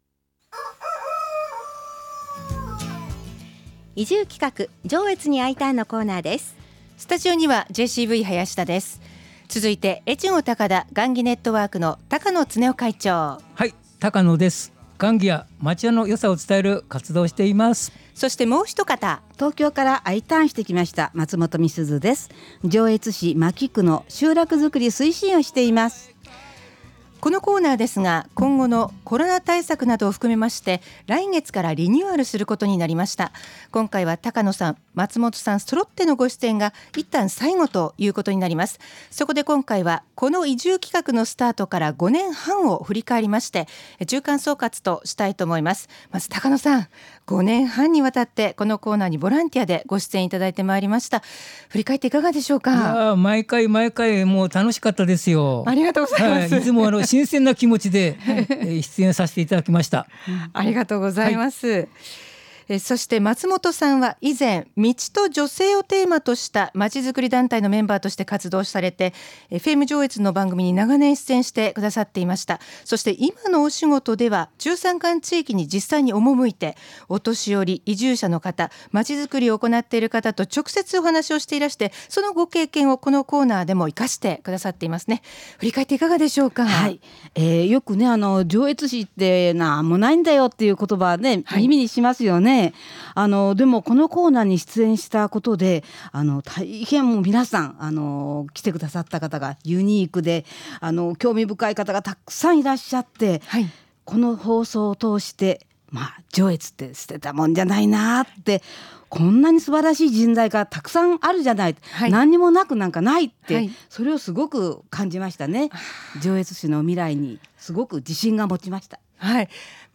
今回は来月からのリニューアルに向けて、 レギュラーメンバー３人がスタジオからお送りしました。